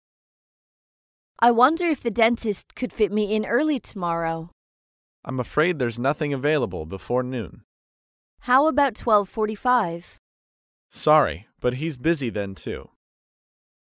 高频英语口语对话 第50期:预约牙医 听力文件下载—在线英语听力室